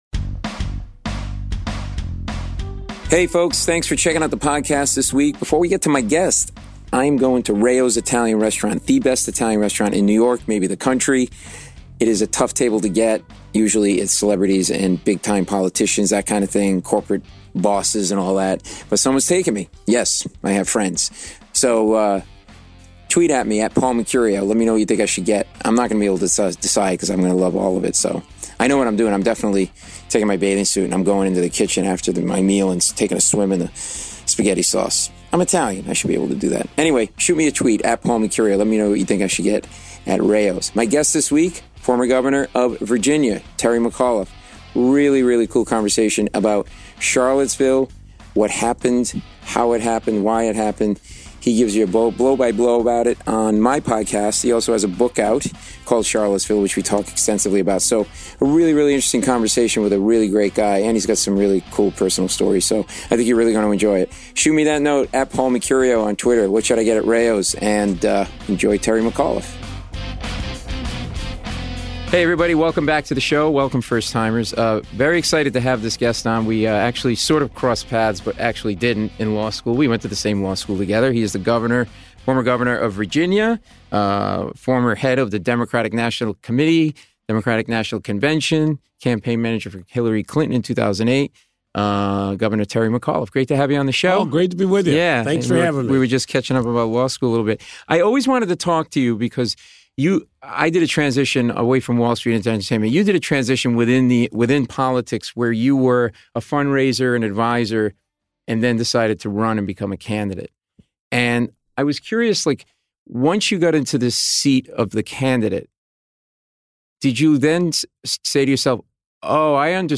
An riveting, fun conversation with the Governor who talks in detail about the historic race rallies in Charlottesville which occurred during his tenure as Governor.